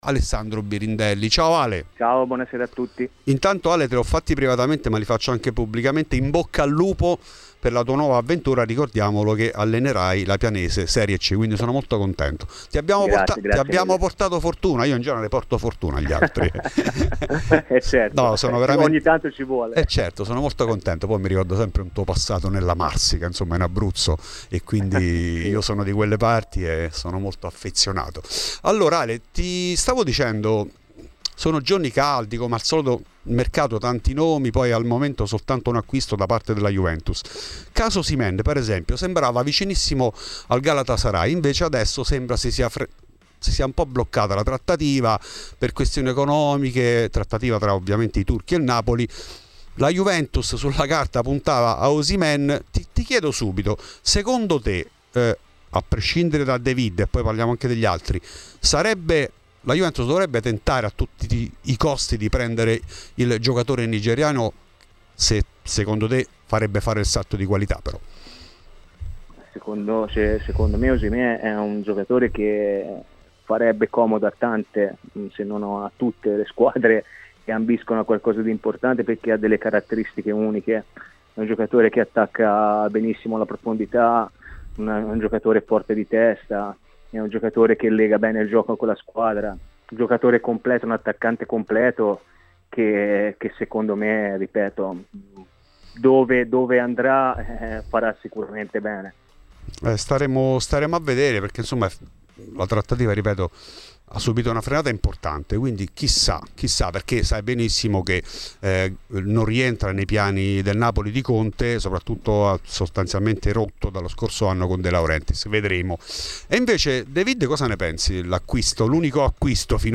In ESCLUSIVA a Fuori di Juve sono tanti gli argomenti trattati da Alessandro Birindelli. L'ex difensore bianconero è convinto che Tudor sia l'uomo giusto per rilanciare la Vecchia Signora.